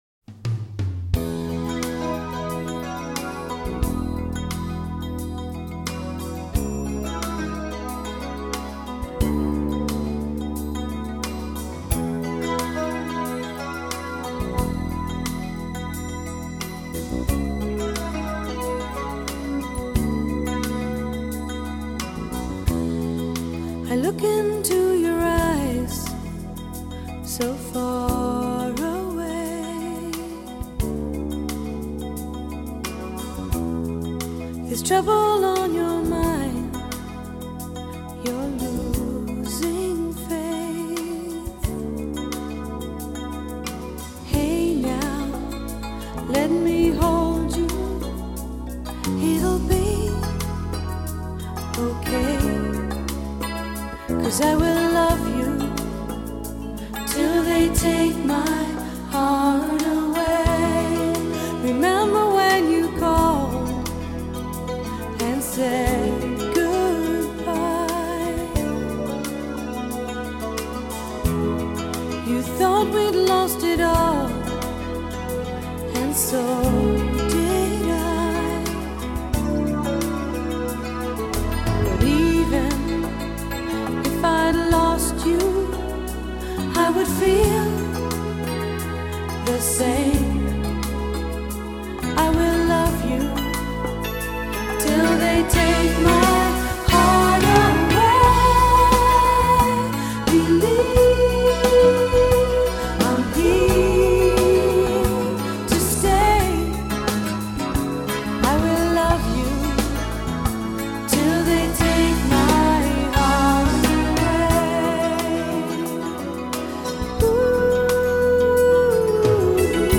★1990 年代人手一張的女聲測試片，以原始母帶精心重製，收錄三首原版未收錄之新曲！